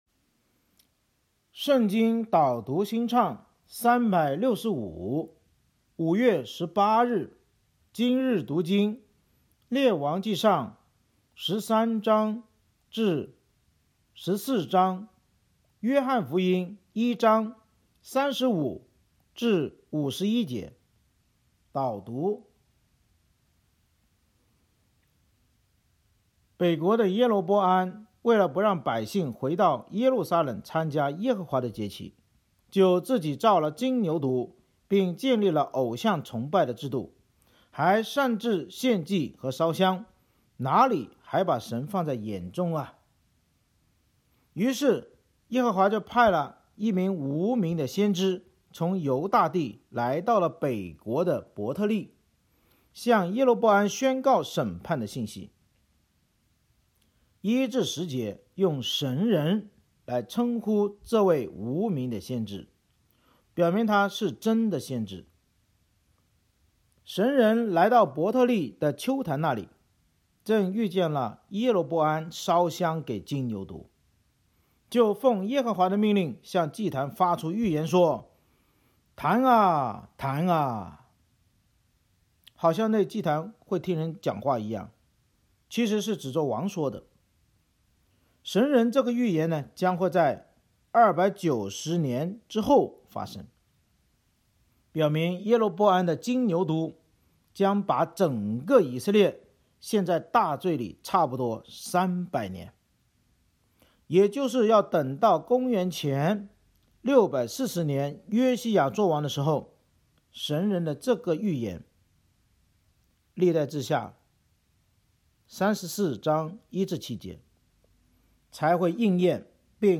圣经导读&经文朗读 – 05月18日（音频+文字+新歌）